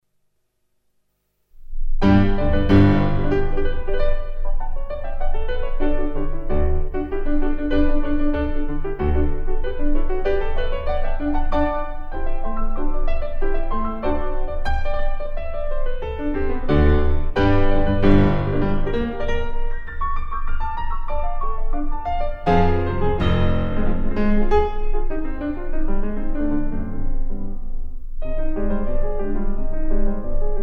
PIANO from Alfred Newman Hall